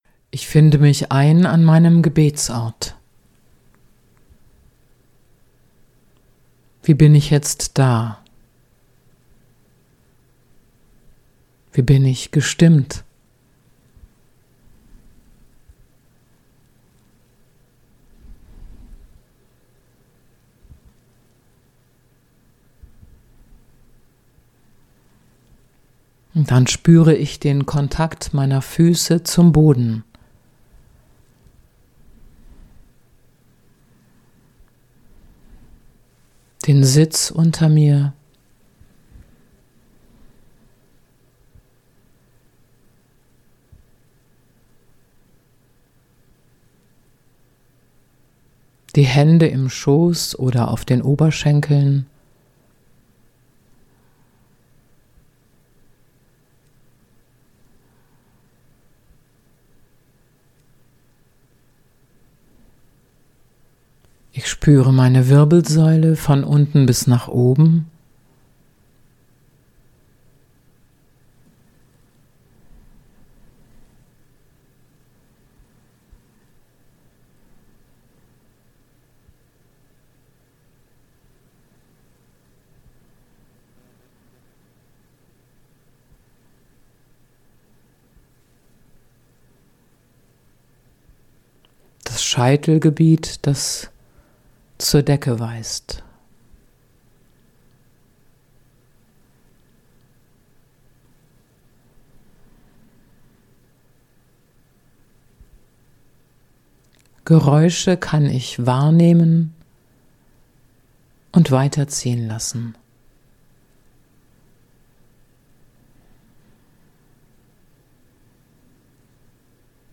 Podcast zu Lukas 1, 26-38 mit einer Schriftbetrachtung